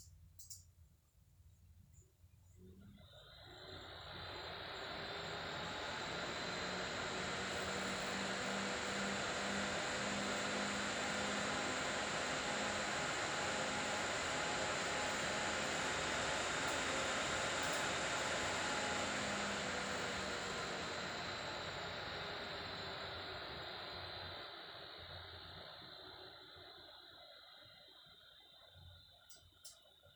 Je genauer und besser du dein Problem beschreibst, desto besser kann dir geholfen werden (zusätzliche Bilder könnten z. B. hilfreich sein): Moin, ich habe bei meiner Grafikkarte das Problem, dass seit kurzem der linke (von der Slotblende ausgesehen) Lüfter deutlich lauter ist als die beiden anderen beiden.
Aufnahme ist aus ca. 15cm mit offenem Gehäuse gemacht worden.
Ich dachte zuerst, dass das Geräusch sich wie Spulenfiepen anhört und habe dementsprechend die Karte undervoltet.